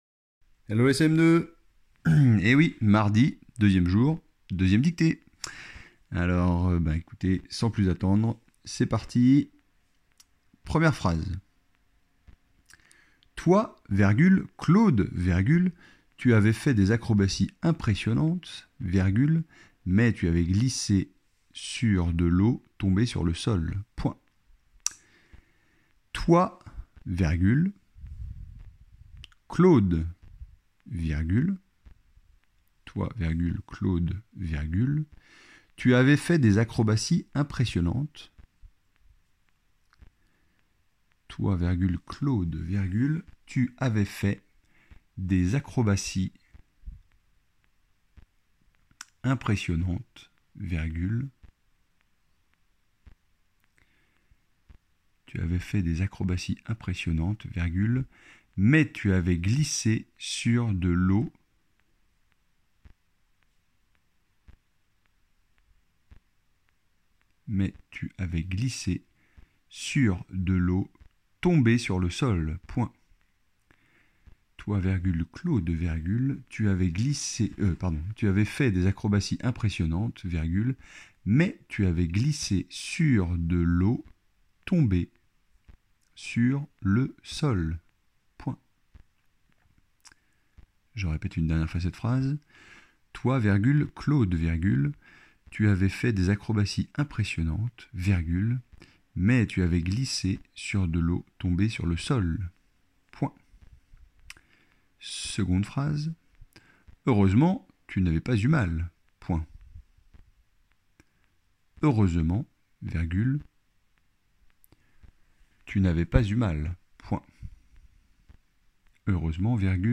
- Dictée :
dictee-mardi.mp3